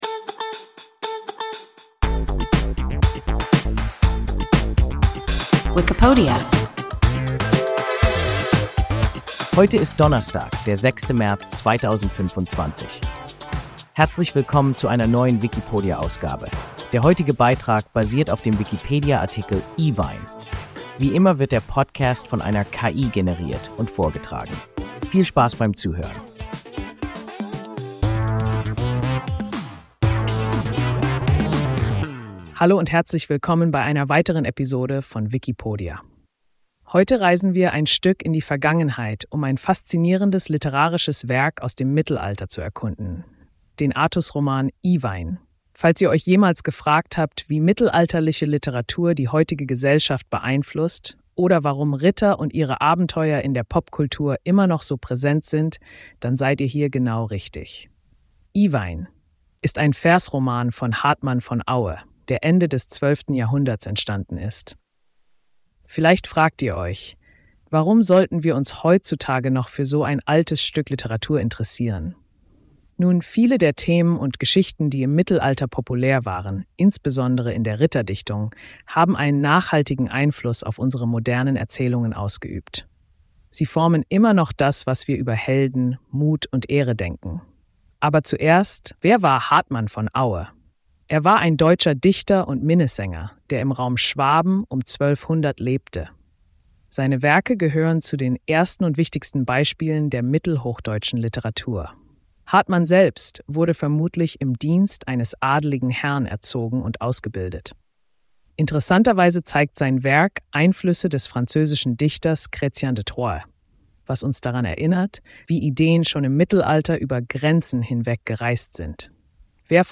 Iwein – WIKIPODIA – ein KI Podcast